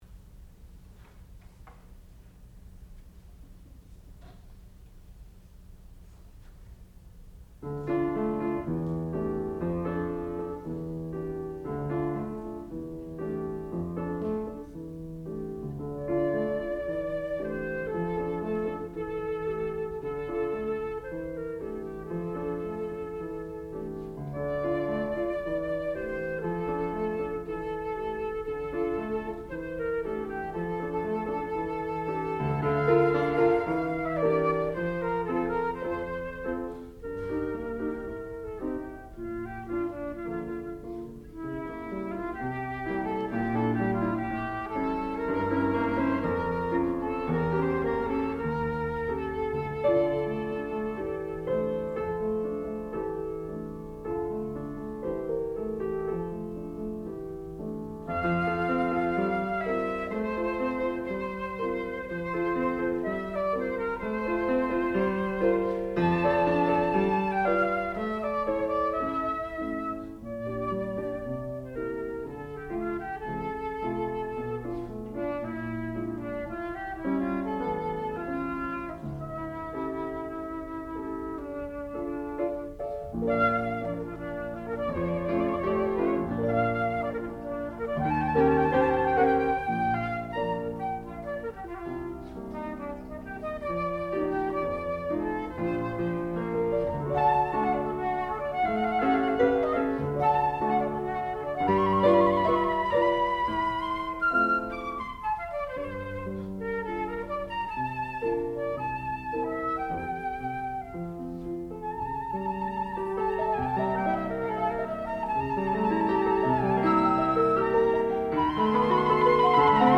sound recording-musical
classical music
Flute
Piano, Faculty recital